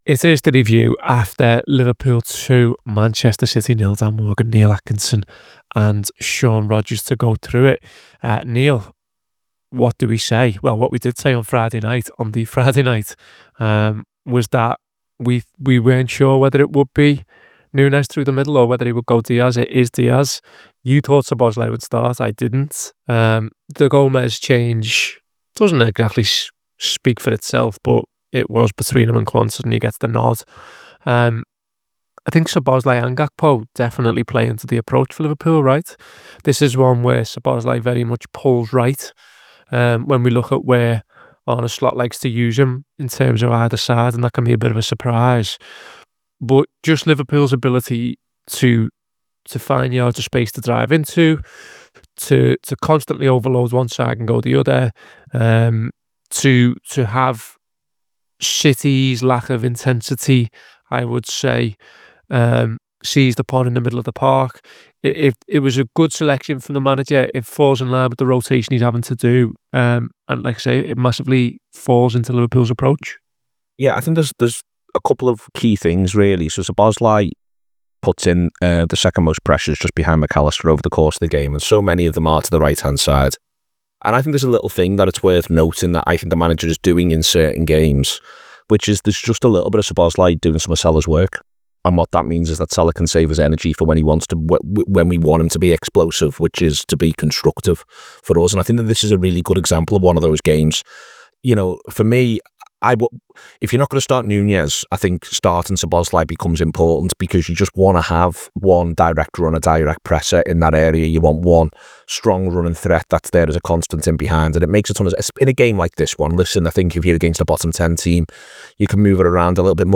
Below is a clip from the show- subscribe for more review chat around Liverpool 2 Manchester City 0…